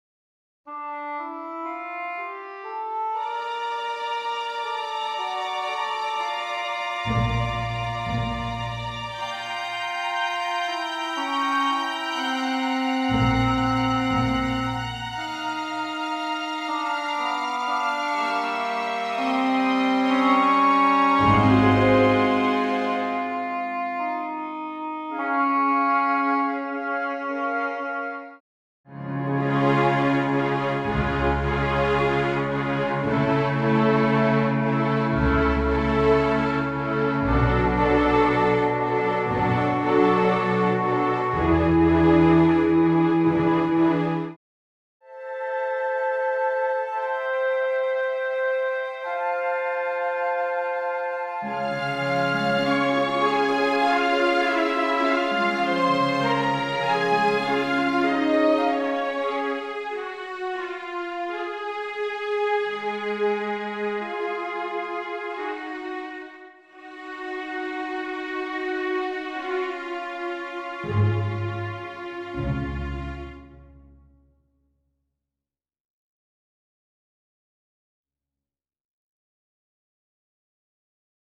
full orchestral accompaniment